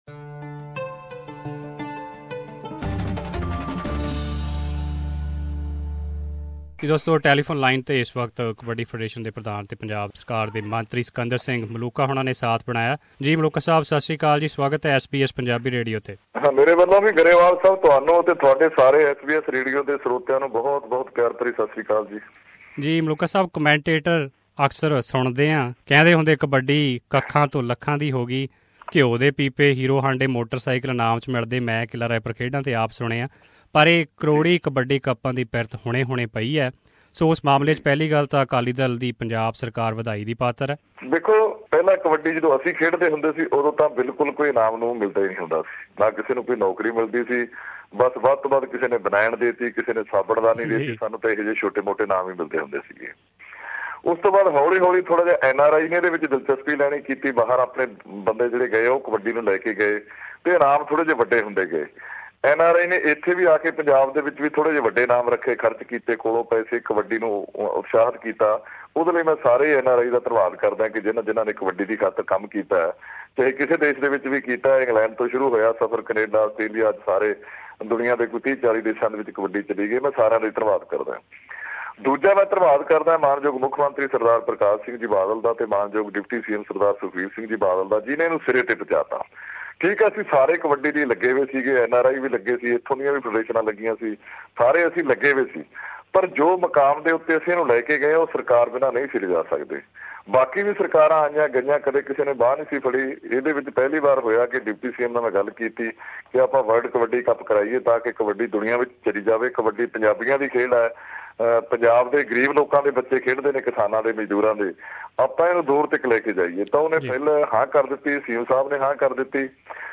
In this segment, we talk to Punjab State Minister Sikander Singh Maluka who is giving an update on the preparations of World Kabaddi Cup scheduled between 6th - 20th Dec 2014. Maluka urged all the foreign Kabaddi Federation to leave their frictions behind for the overall welfare of the game of Kabaddi.